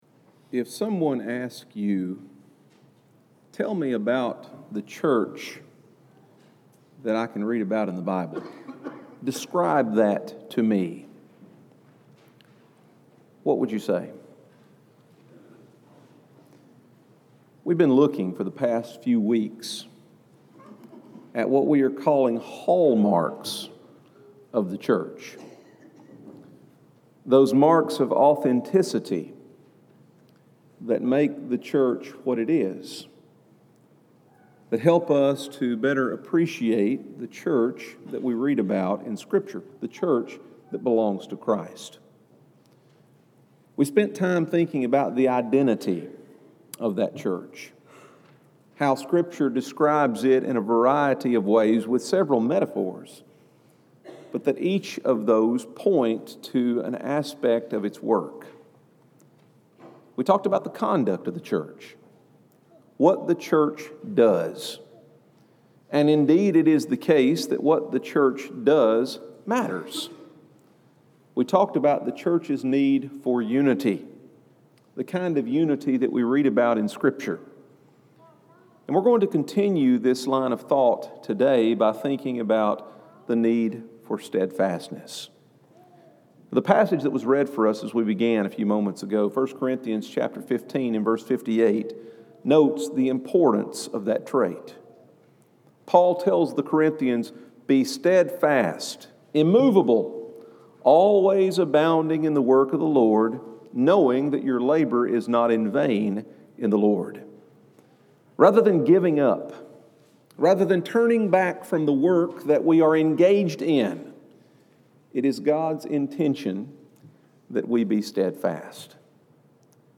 This lesson explores the steadfastness of the Church.